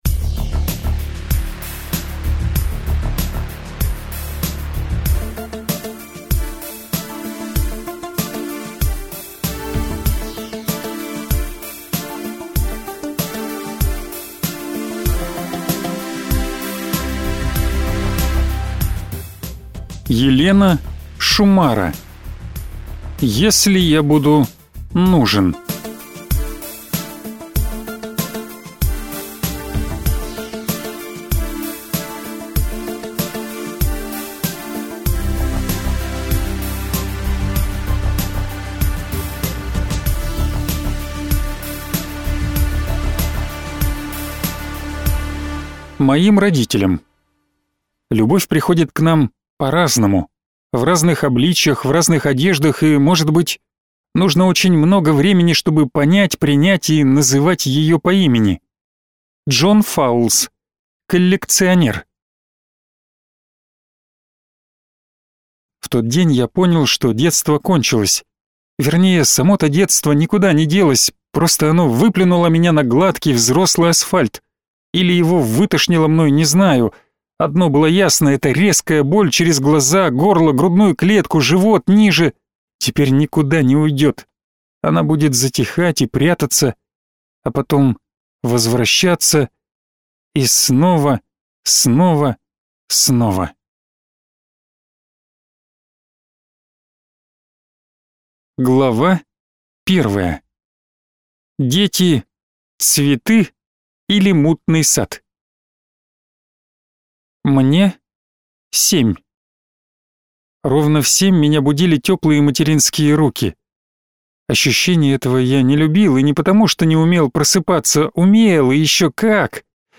Аудиокнига Если я буду нужен | Библиотека аудиокниг